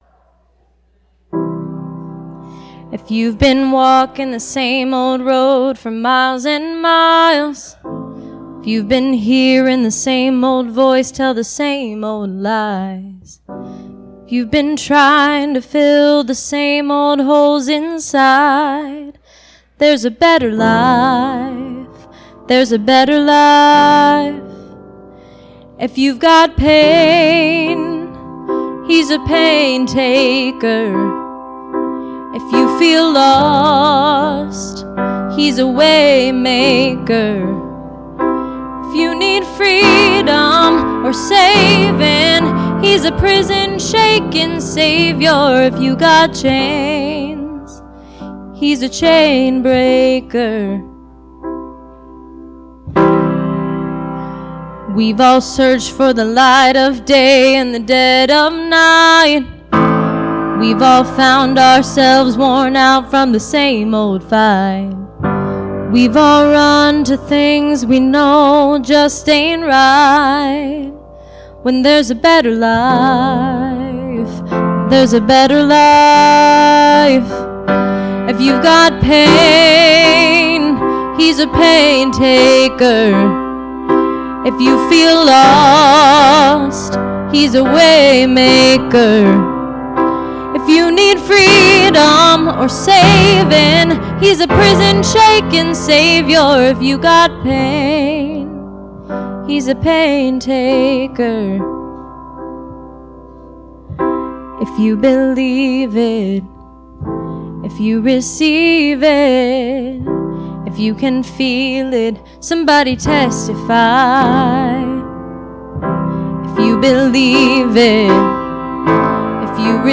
Please, click the arrow below to hear this week's service.